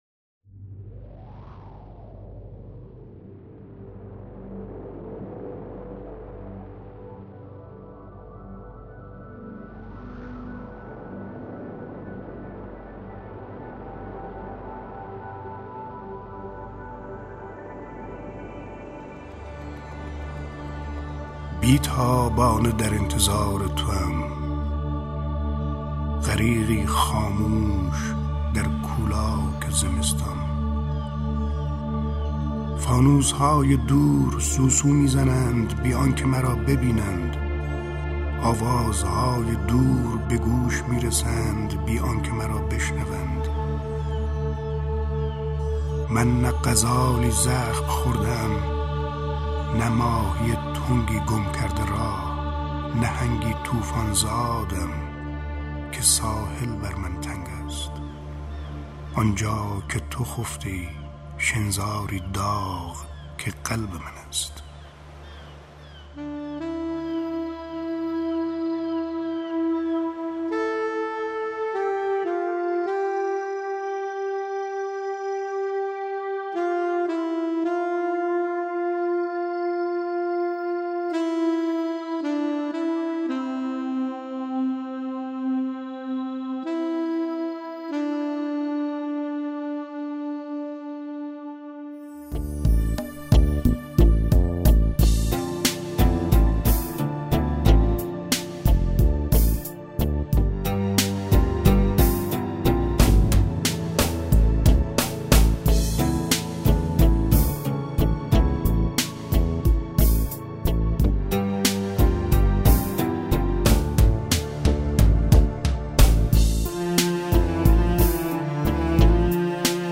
دانلود دکلمه بی تابانه در انتظار توام با صدای شمس لنگرودی با متن دکلمه
گوینده :   [شمس لنگرودی]